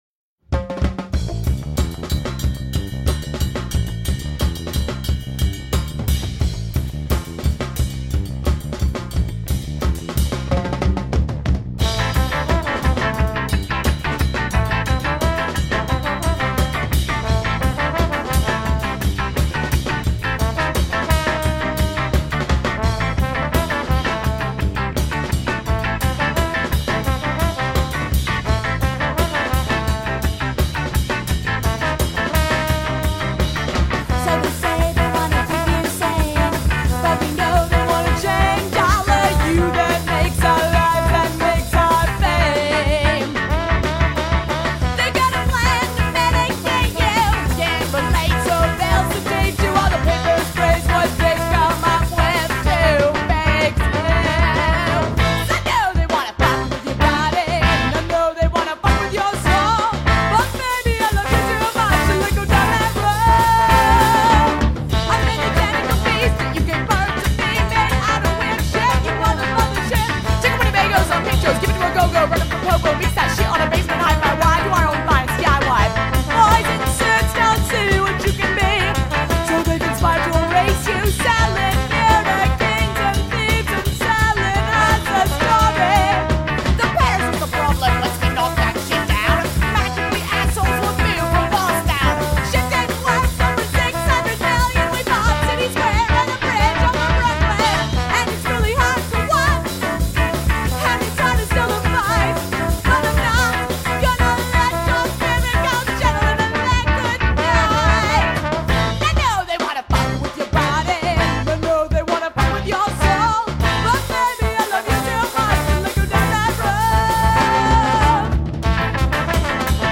Punk
Experimental